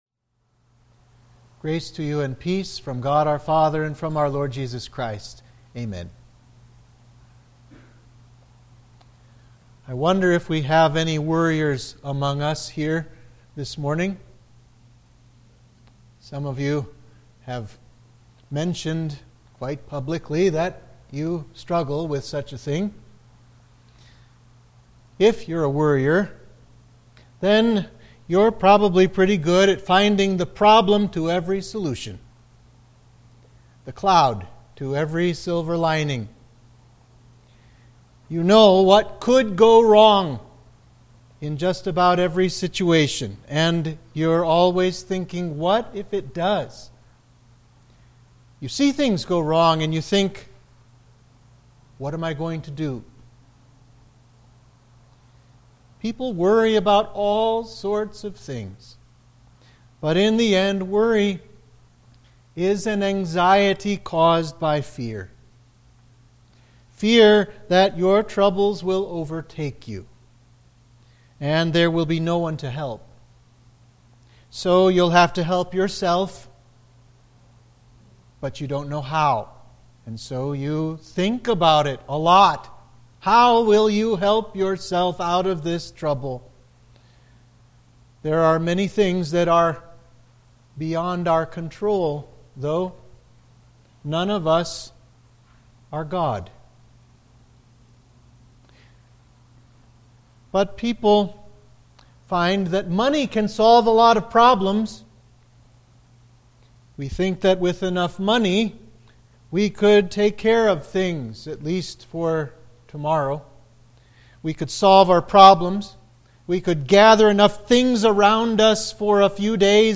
Sermon for Trinity 15